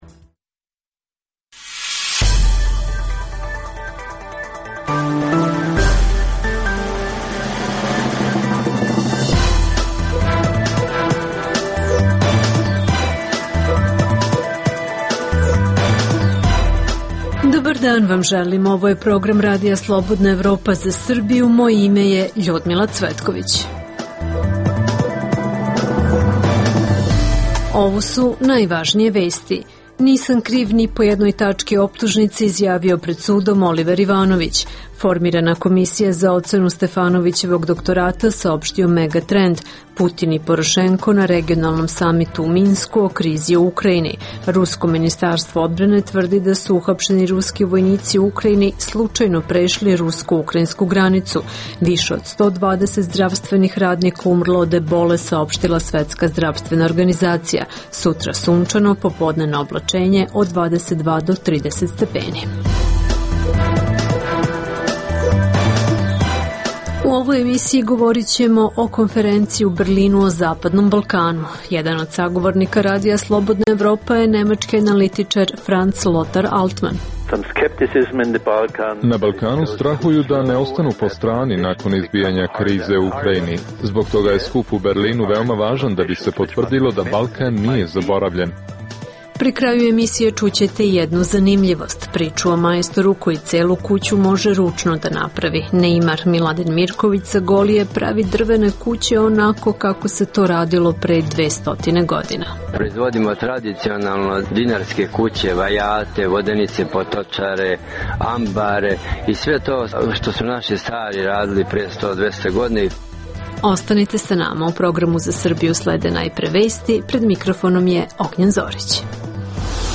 Emisija namenjena slušaocima u Srbiji. Sadrži lokalne, regionalne i vesti iz sveta, tematske priloge o aktuelnim dešavanjima iz oblasti politike, ekonomije i slično, te priče iz svakodnevnog života ljudi, kao i priloge iz sveta.